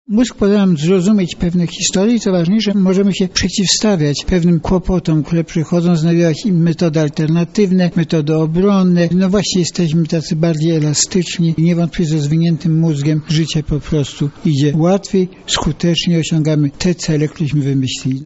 Między innymi o tym przekonywał profesor Jerzy Vetulani w trakcie VII Lubelskiego Sympozjum Doktorantów Nauk Farmaceutycznych wraz z Otwartym Seminarium o Życiu.
Ważne dla człowieka jest aby osiągnął indywidualny poziom satysfakcji, mówi prof. Jerzy Vetulani.